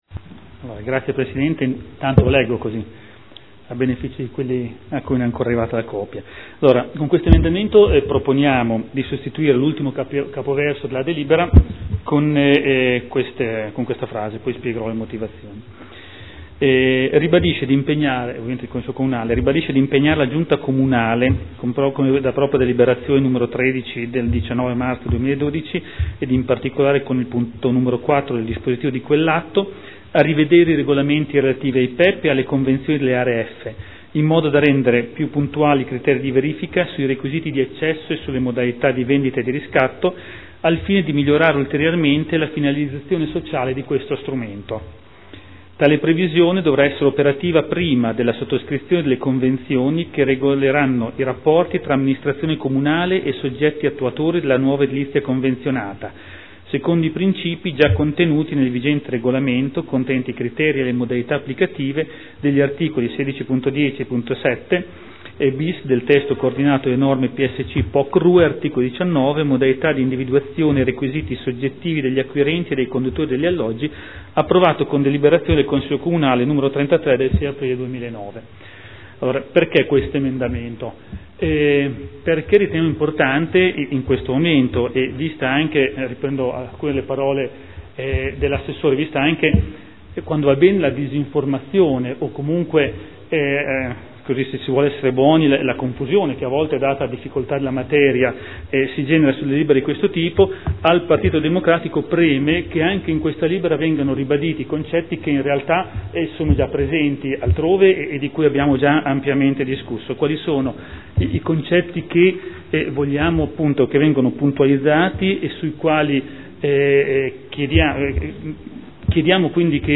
Gian Domenico Glorioso — Sito Audio Consiglio Comunale
Seduta del 15/07/2013 Perequazione nelle Zone F – Revisione dell’art. 16.7 bis POC. Presenta emendamento.